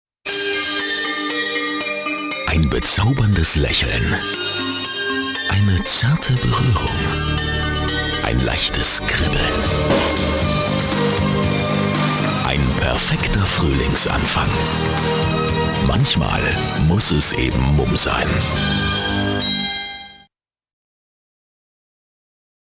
Tiefe, eindringliche Stimme.
Sprechprobe: Industrie (Muttersprache):
german voice over artist